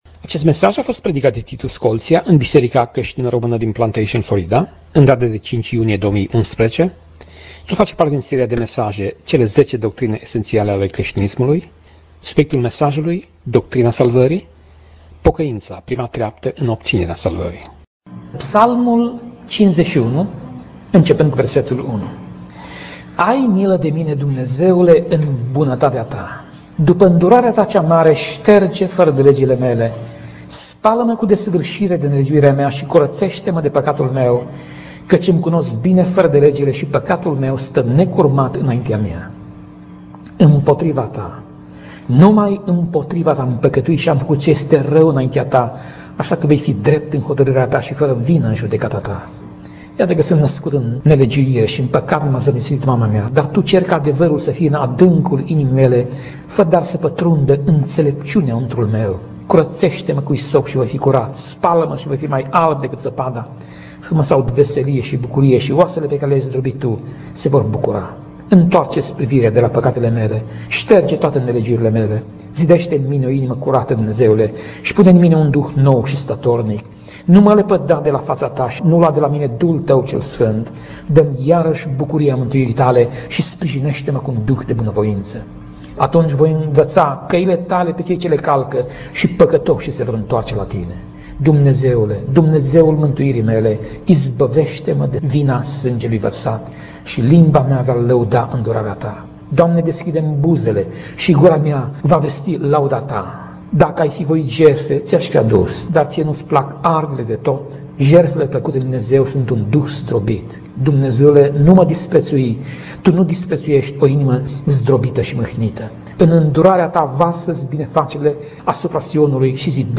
Tip Mesaj: Predica Serie: Zece Doctrine Esentiale ale Crestinismului